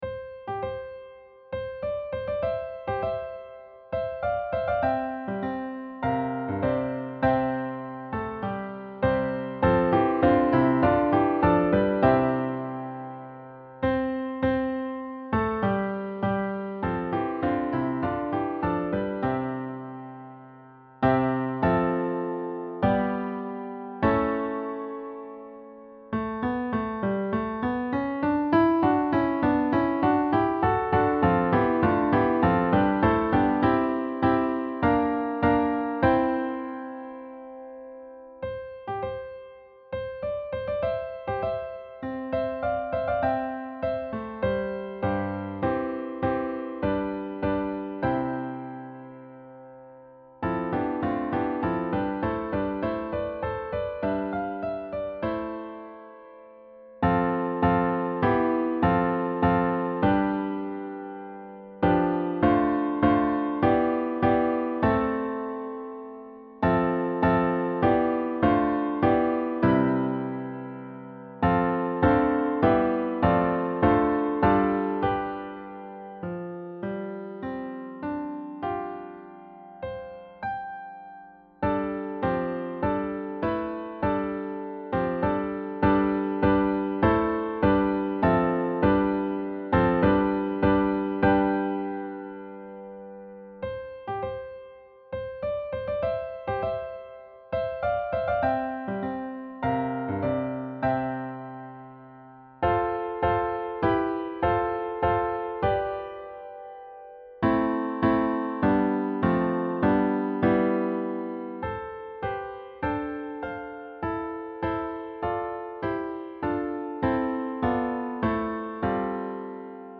• Music Type: Choral
• Voicing: SATB
• Accompaniment: Organ, Trombone, Trumpet, Tuba